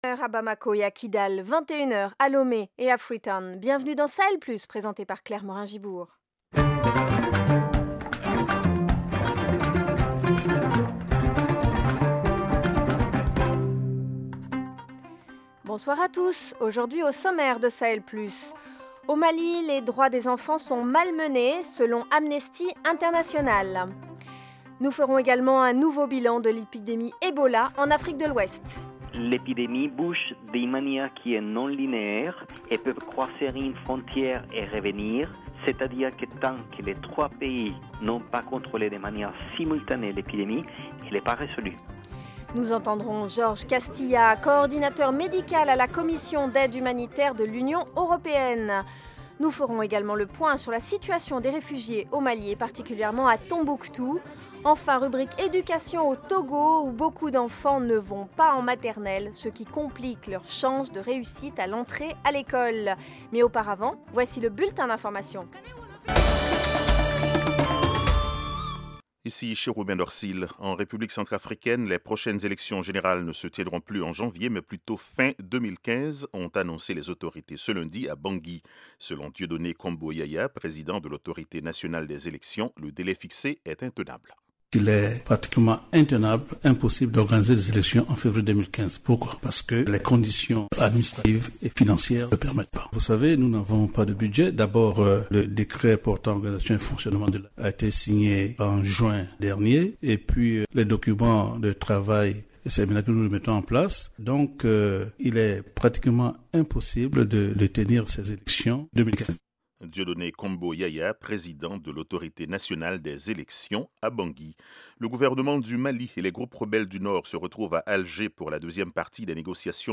Au programme : au Mali, les droits des enfants sont malmenés au Mali selon Amnesty International. Bilan de l’épidémie Ebola en Afrique de l’Ouest. Reportage : les difficultés des réfugiés à Tombouctou au Mali. Education : au Togo, peu d’enfants vont à la maternelle, étape pourtant importante.